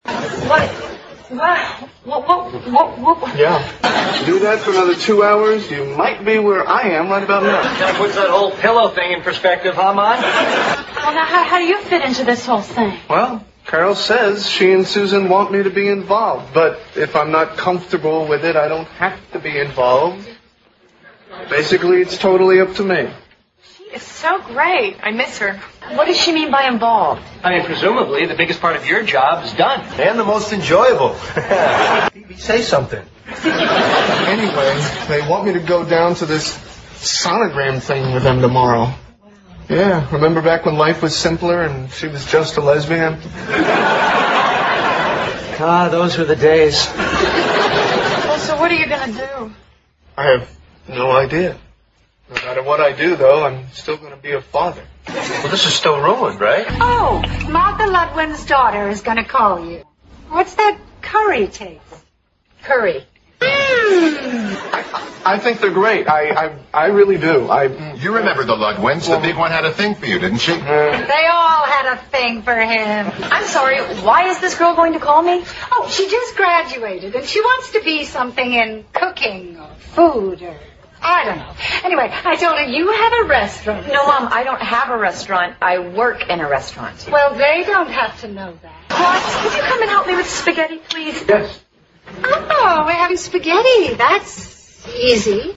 在线英语听力室老友记精校版第1季 第16期:参加助产培训班(4)的听力文件下载, 《老友记精校版》是美国乃至全世界最受欢迎的情景喜剧，一共拍摄了10季，以其幽默的对白和与现实生活的贴近吸引了无数的观众，精校版栏目搭配高音质音频与同步双语字幕，是练习提升英语听力水平，积累英语知识的好帮手。